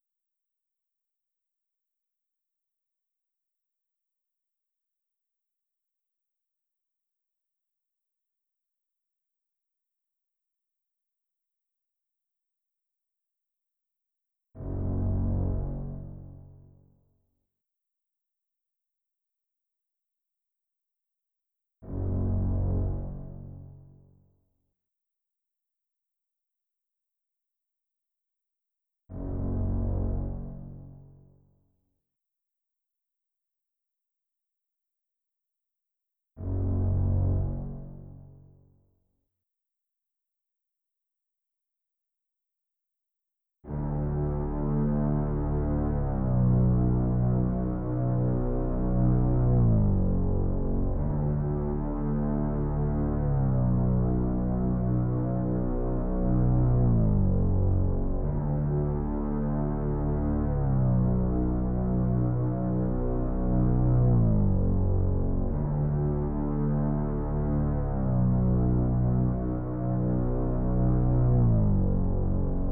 Drive Thru String.wav